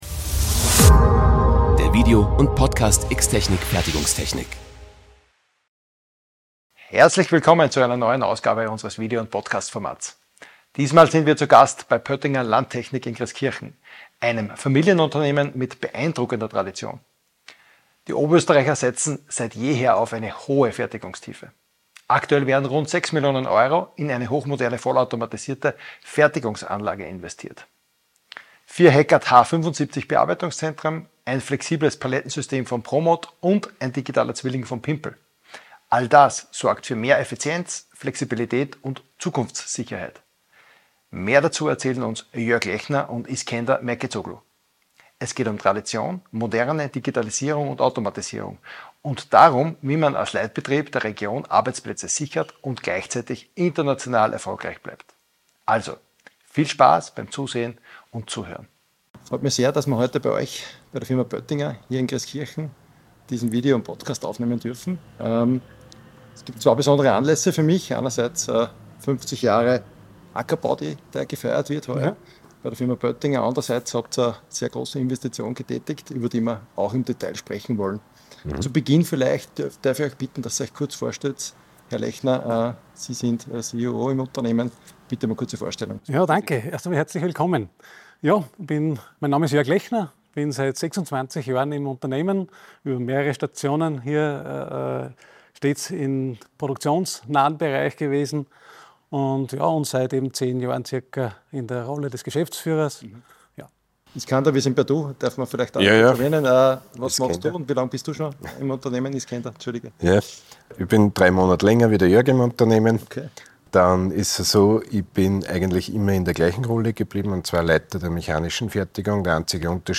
Aktuell feiert Pöttinger 50 Jahre Ackerbau – und gleichzeitig investierte man rund 6 Millionen Euro in eine hochmoderne, vollautomatisierte Fertigungsanlage: vier Heckert H75 Bearbeitungszentren, ein flexibles Palettensystem von Promot und ein Digitaler Zwilling von Pimpel – all das sorgt für mehr Effizienz, Flexibilität und Zukunftssicherheit. Im Gespräch